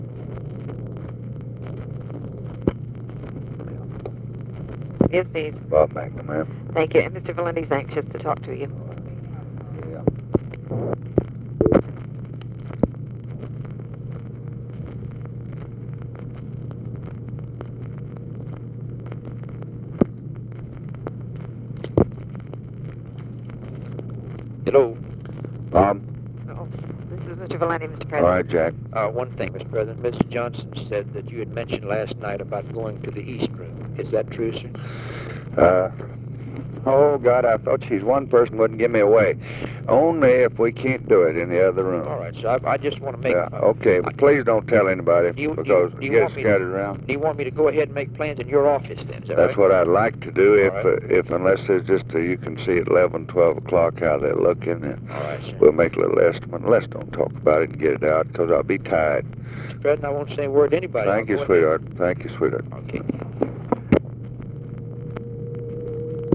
Conversation with JACK VALENTI and TELEPHONE OPERATOR, March 13, 1965
Secret White House Tapes